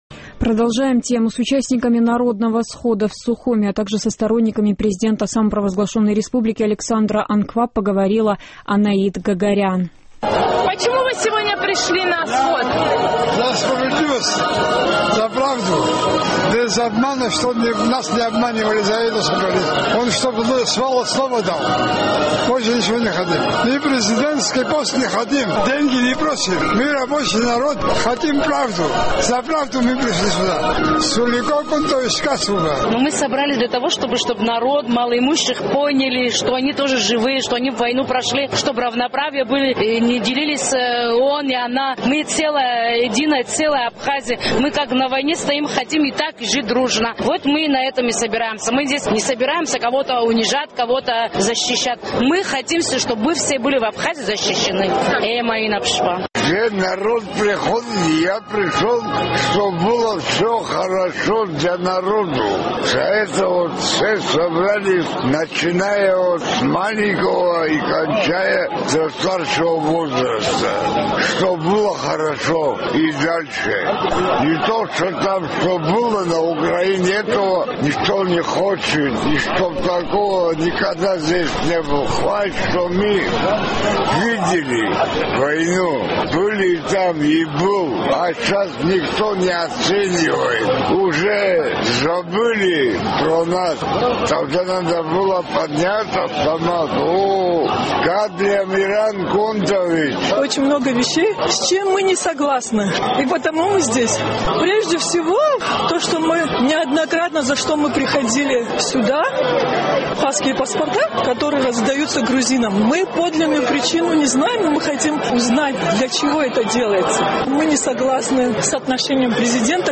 Голоса абхазского схода
Вчера в центре Сухума прошел массовый митинг оппозиции, участники которого добивались отставки правительства, генпрокурора и глав восточных районов Абхазии. Наш корреспондент пообщалась с его участниками.